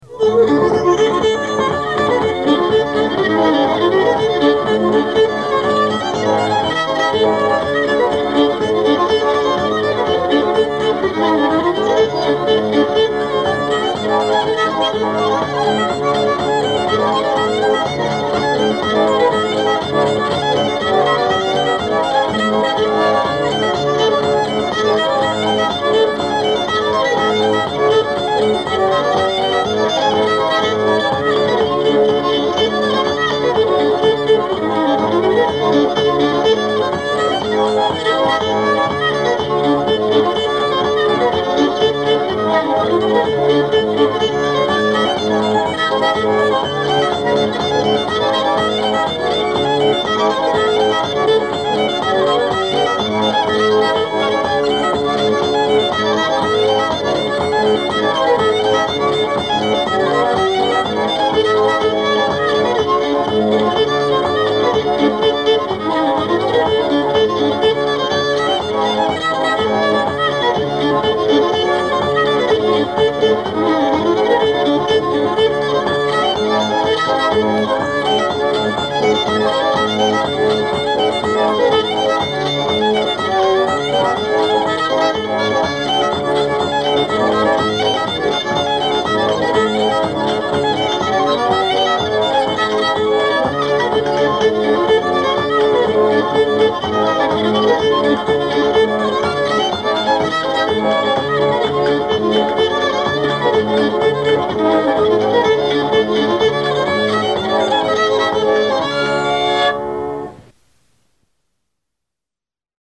pump organ